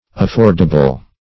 affordable - definition of affordable - synonyms, pronunciation, spelling from Free Dictionary
Affordable \Af*ford"a*ble\, a.